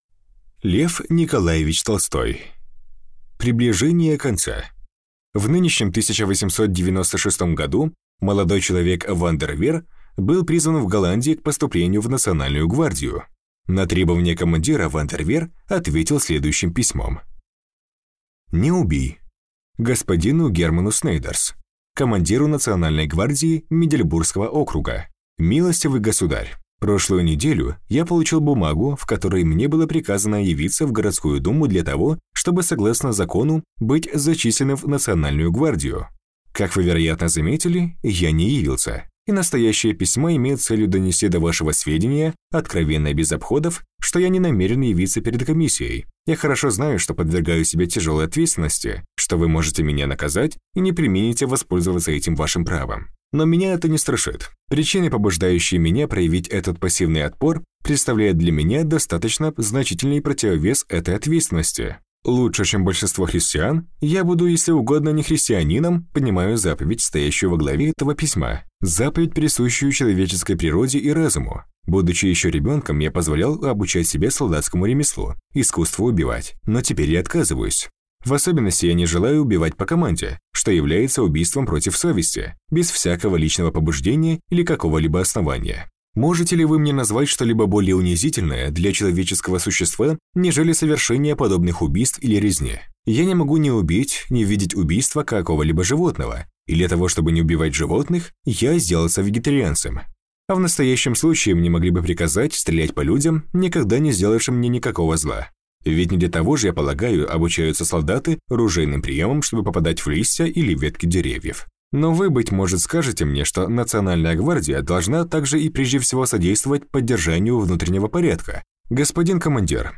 Аудиокнига Приближение конца | Библиотека аудиокниг